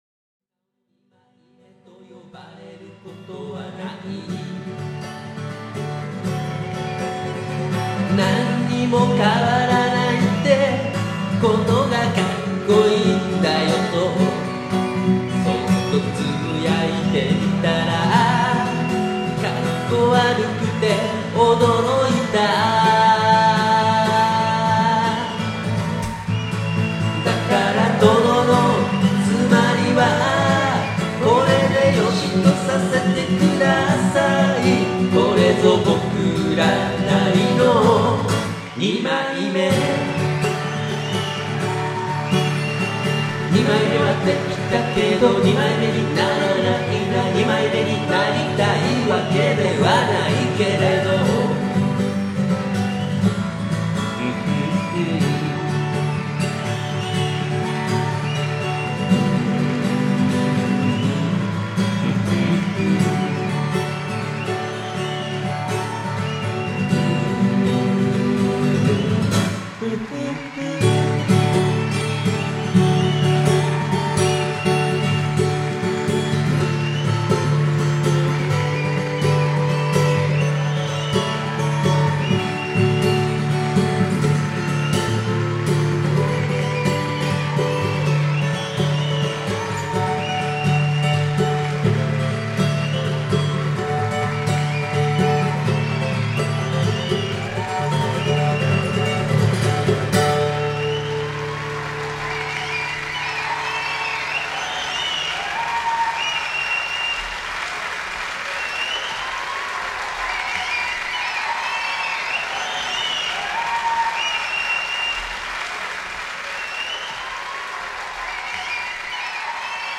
ライブ